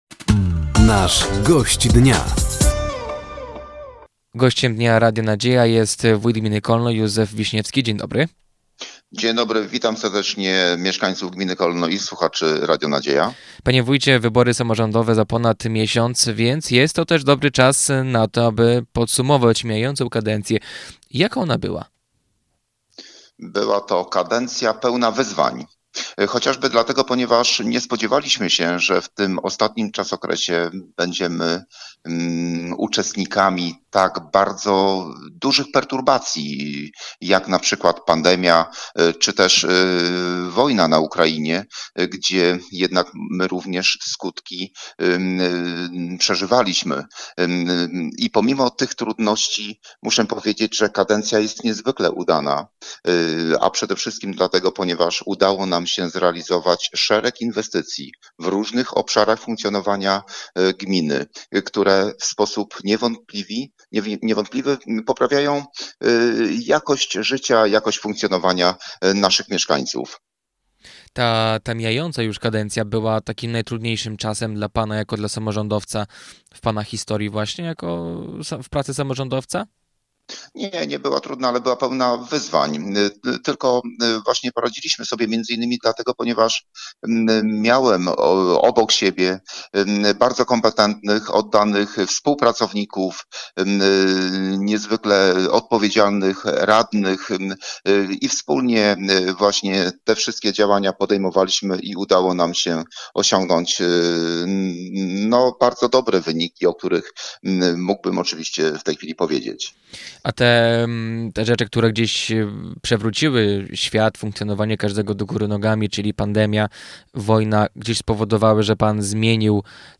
Dzisiejszym Gościem Dnia Radia Nadzieja był wójt Gminy Kolno, Józef Bogdan Wiśniewski.